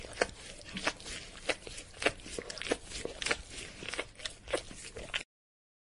Dog_Eating.mp3